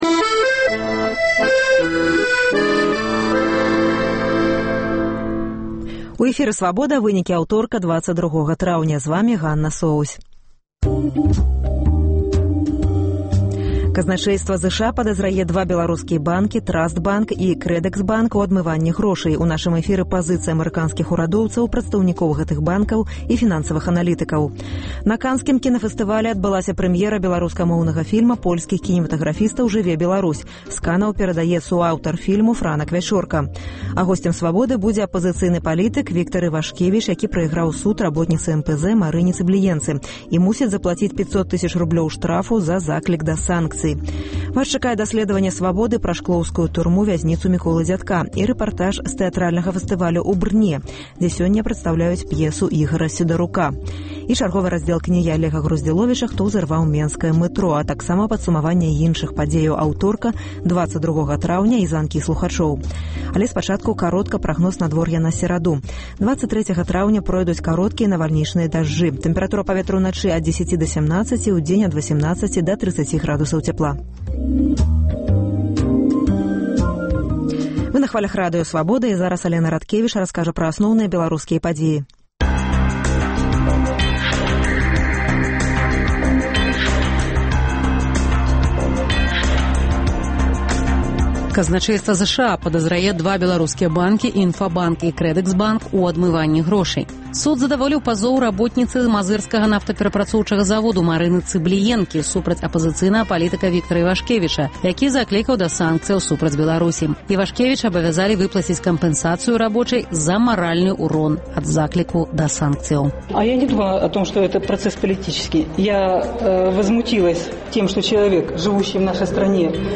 Вечаровы госьць, сацыяльныя досьледы, галасы людзей